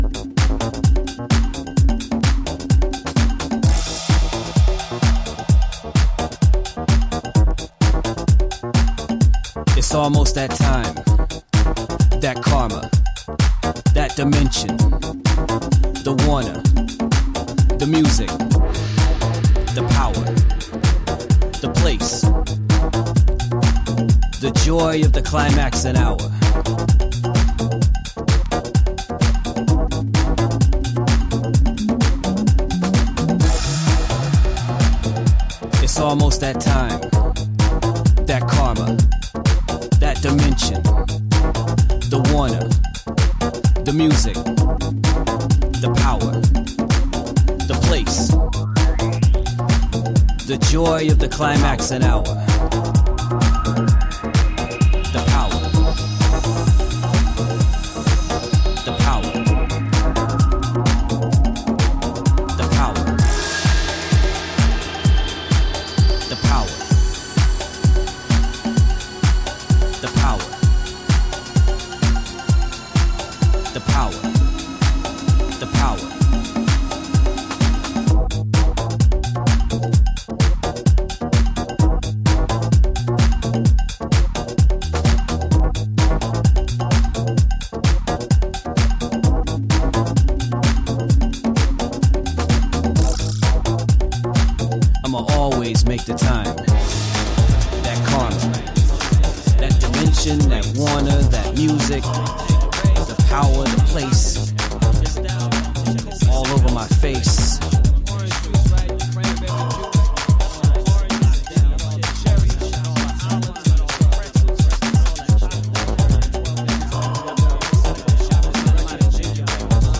ブレイク明け昇天必至なトランシーさマシマシの3トラック。